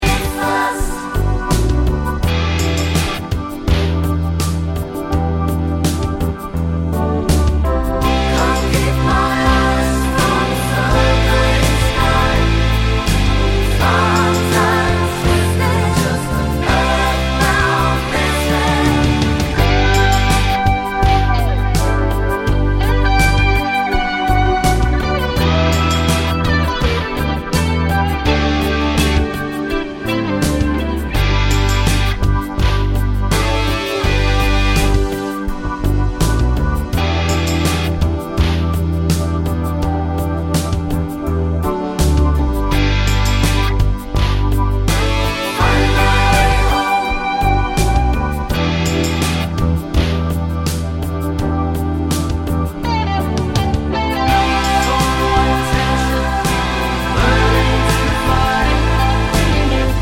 With Traffic Control Spoken Bits Rock 4:57 Buy £1.50